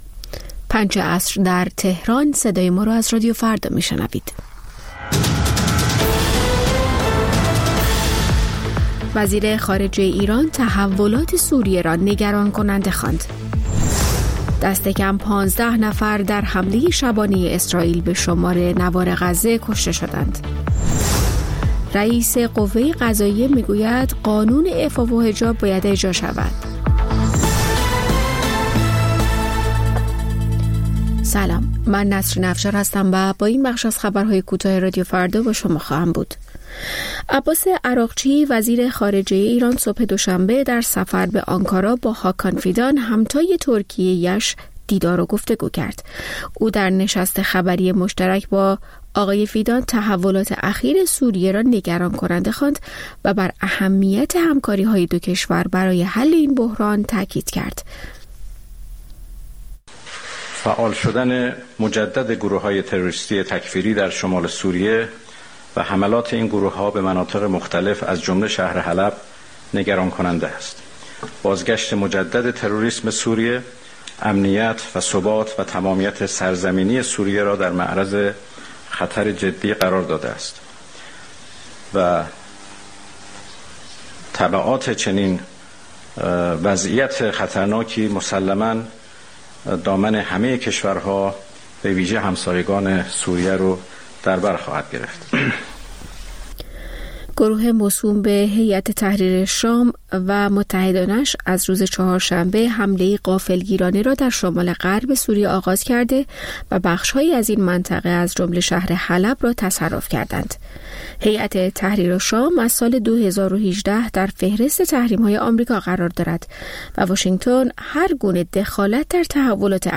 سرخط خبرها ۱۷:۰۰